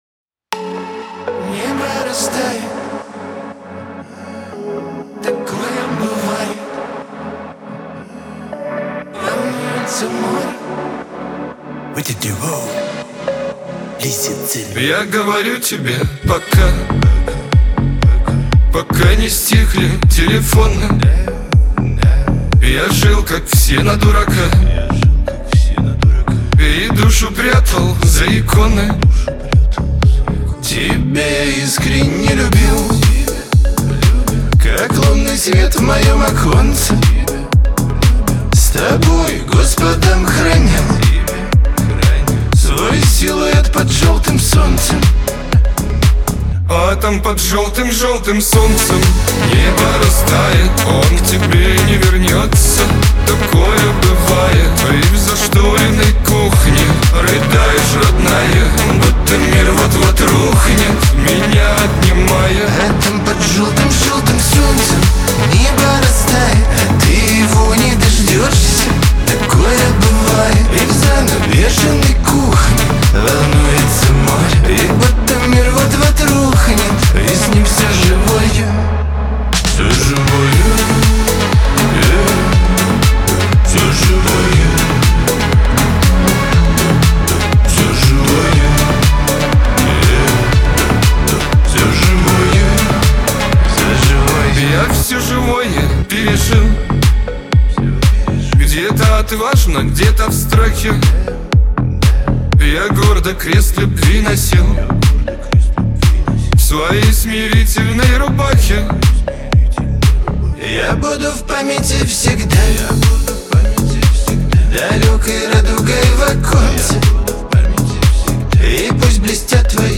Кавер-версия
грусть
Лирика , Шансон